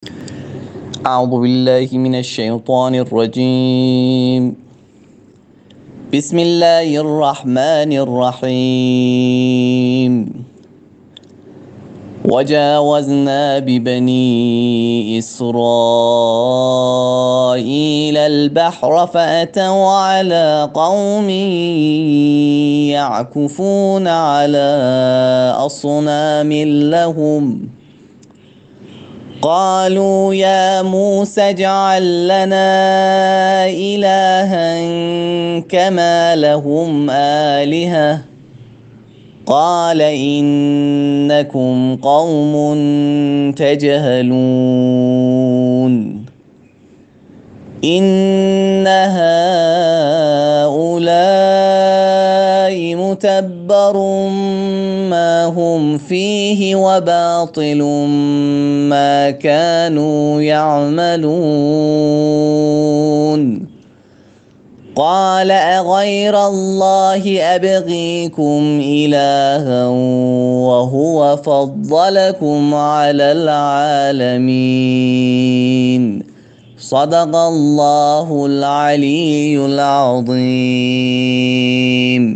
هنگام تلاوت آیاتی از قرآن کریم